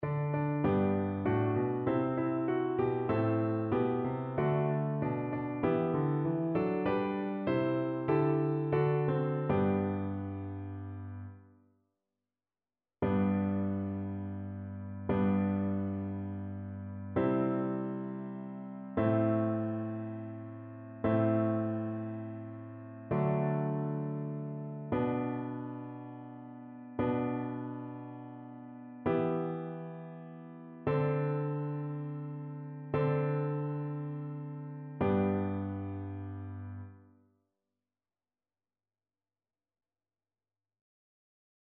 annee-a-temps-ordinaire-20e-dimanche-psaume-66-satb.mp3